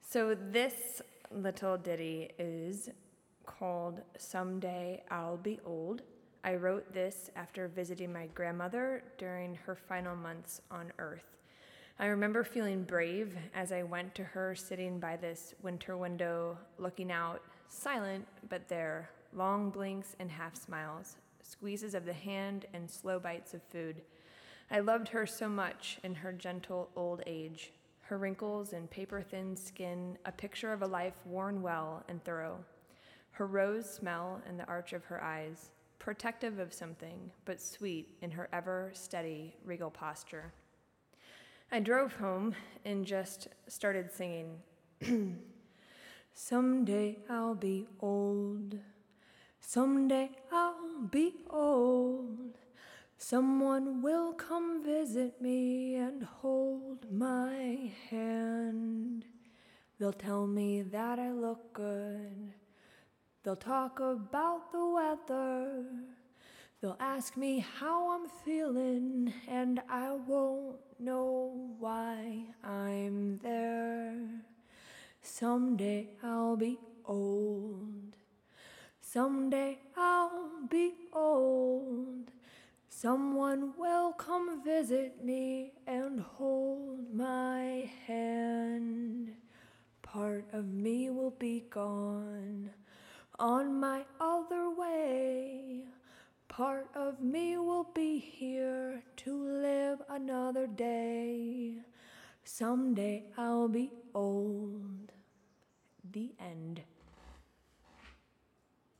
This is my first post posting vocals.